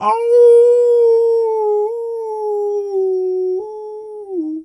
wolf_2_howl_soft_06.wav